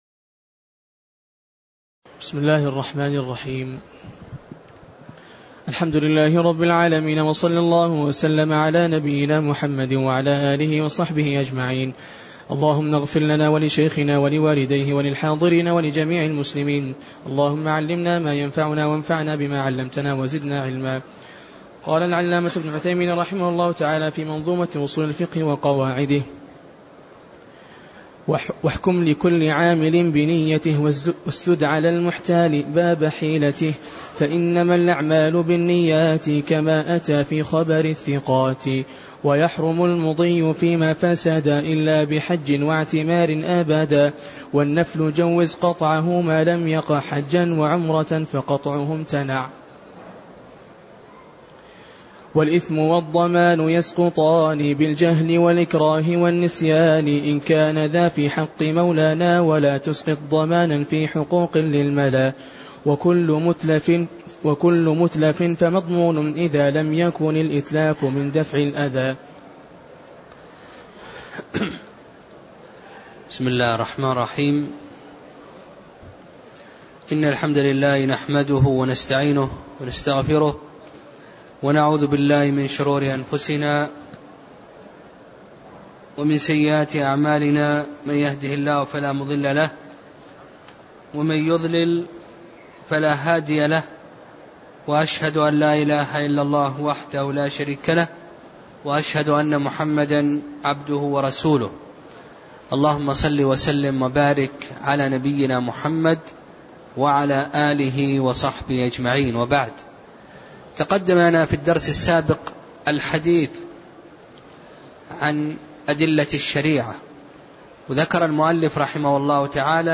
تاريخ النشر ٦ شعبان ١٤٣٤ هـ المكان: المسجد النبوي الشيخ